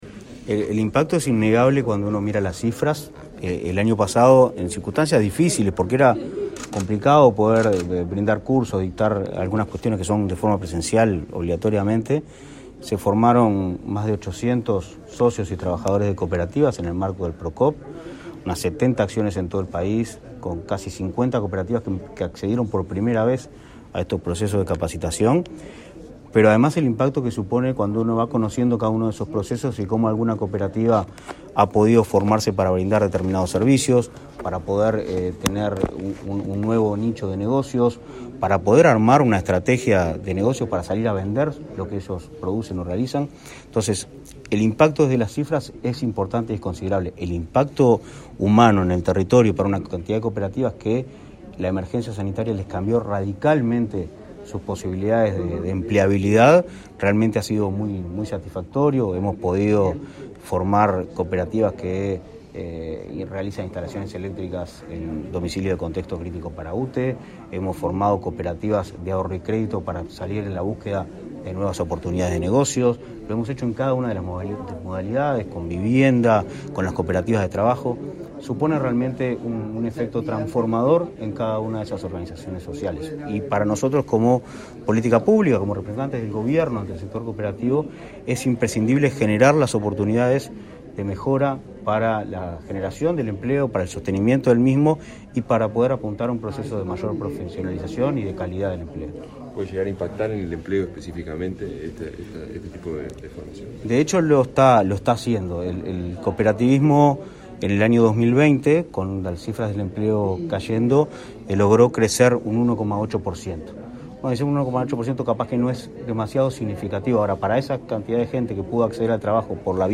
Declaraciones a la prensa del director de Inacoop